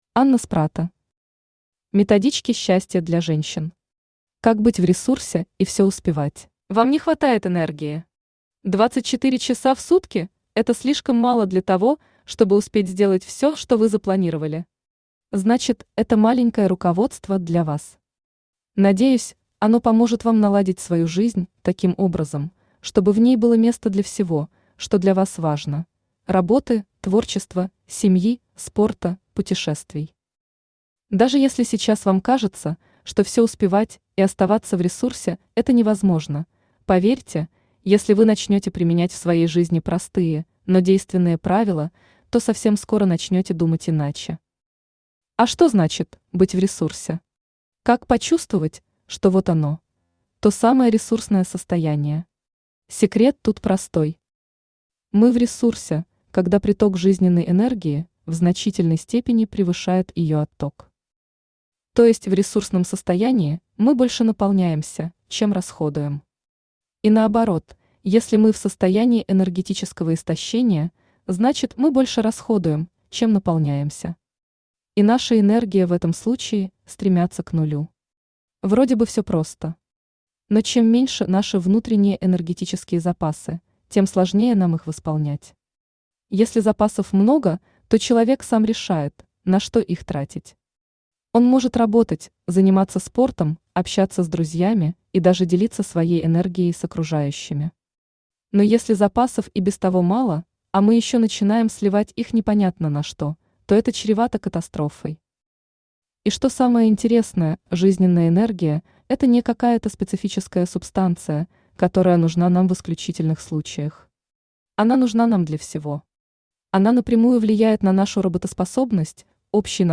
Как быть в ресурсе и все успевать Автор Анна Спратто Читает аудиокнигу Авточтец ЛитРес.